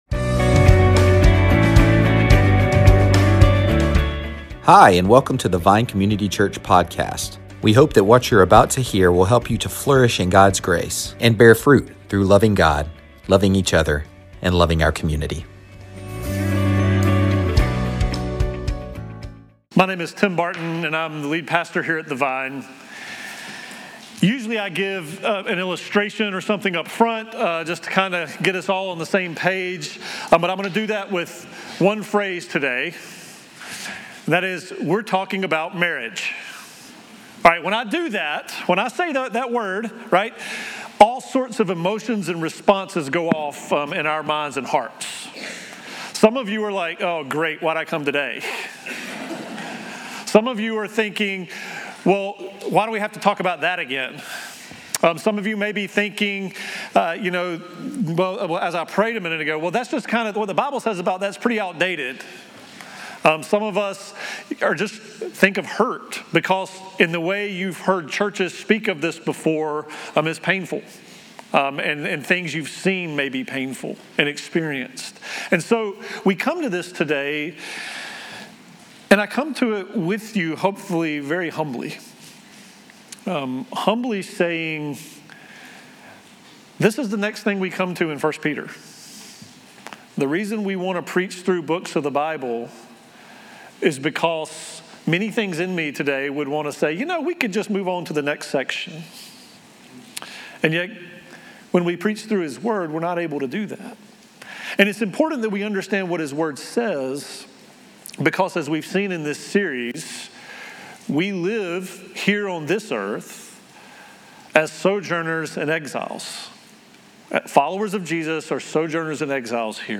Sermons |